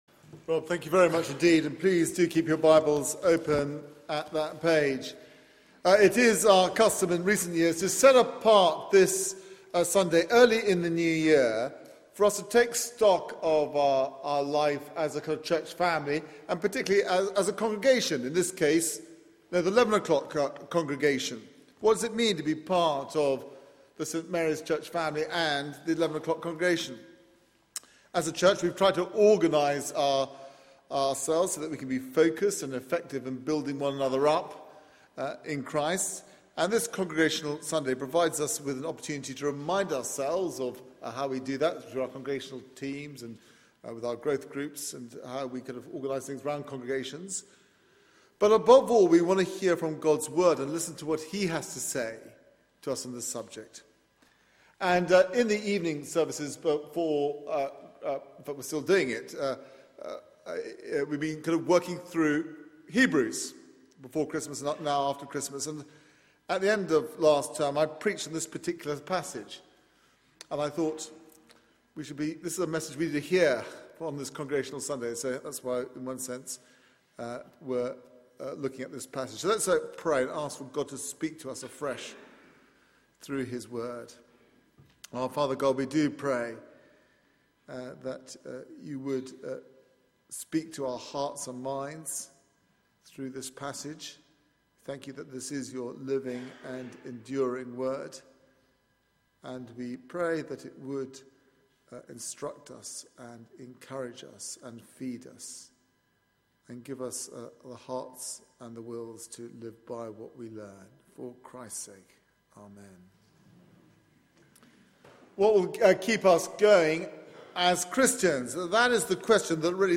Media for 9:15am Service
Sermon